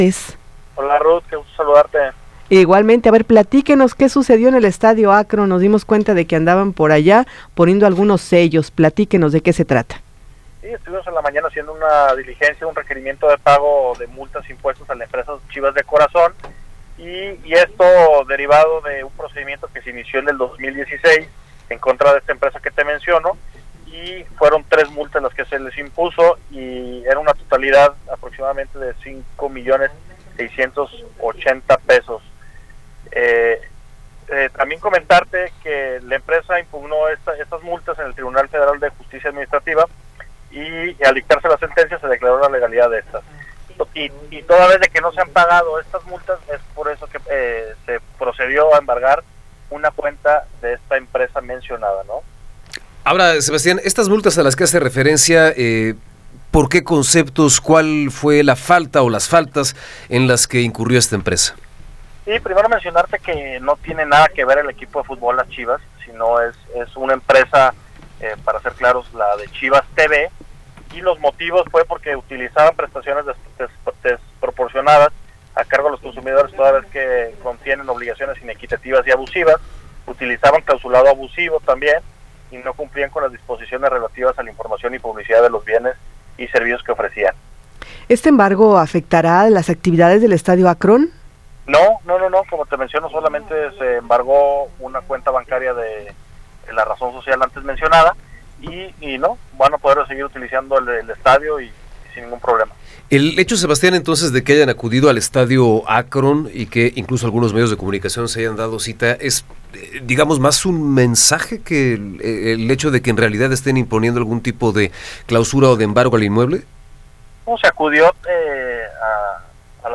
Entrevista con Sebastián Hernández